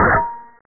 SWORD.mp3